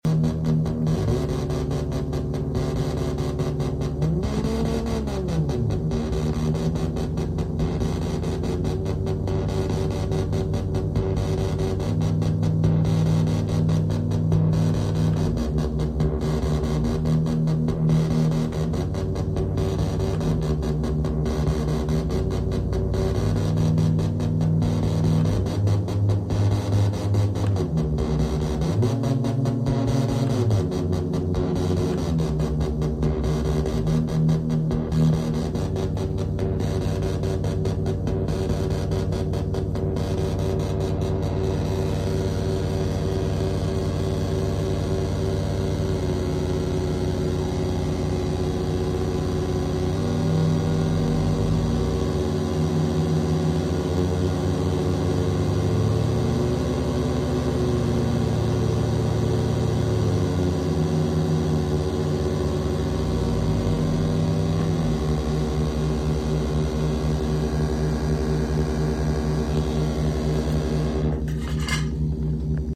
working on my disgusting DSP based VCO bank in pure data w/ controlled feedback and noise.